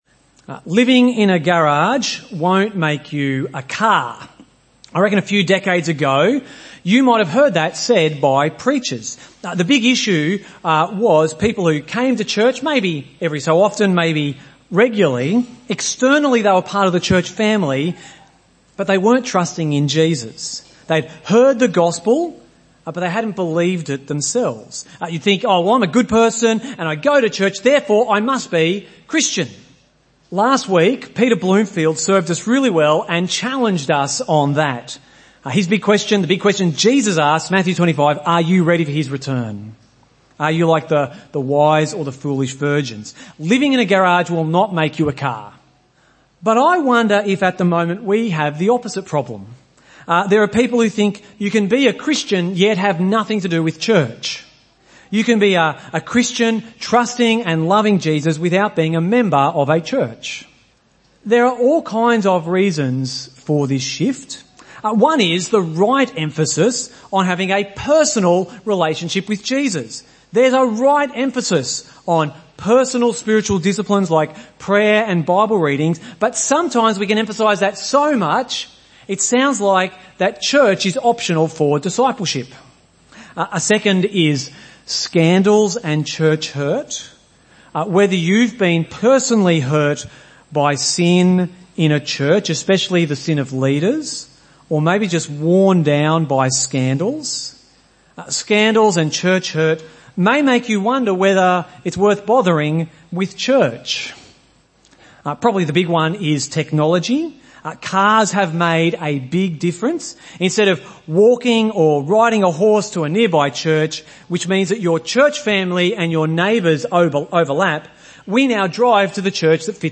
Weekly sermon podcast from Gympie Presbyterian Church